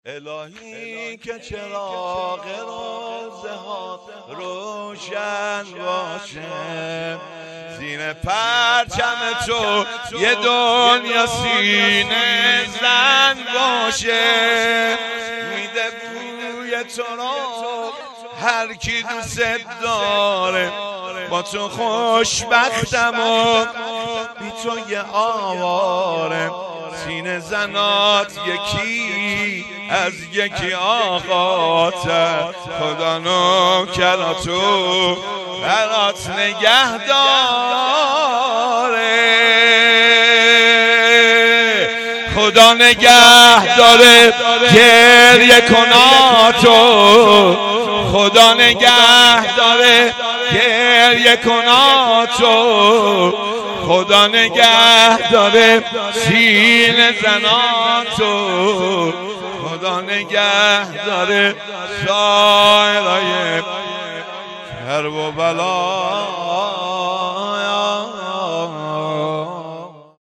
شور پایانی
شهادت حضرت محسن ابن علی علیه السلام ۱۴۰۲